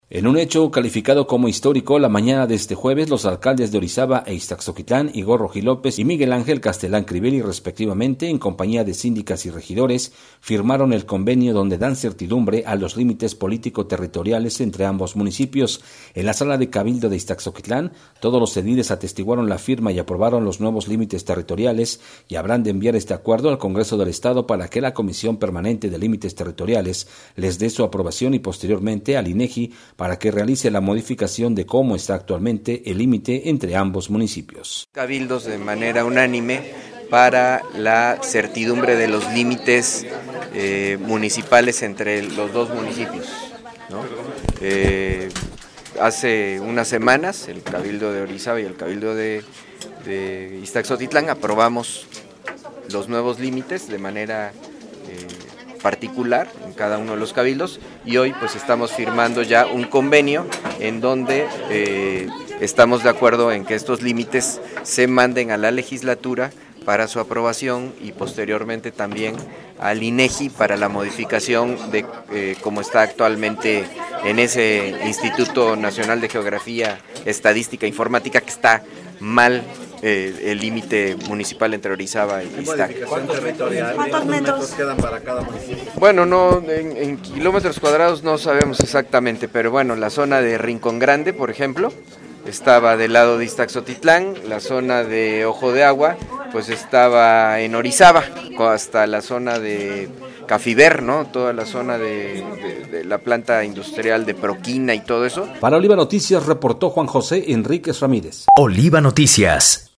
Entrevistados al finalizar el acto protocolario, los alcaldes de Ixtaczoquitlán y Orizaba comentaron que se acercan nuevas cosas para ambos municipios y trabajar de común acuerdo en beneficio de su población.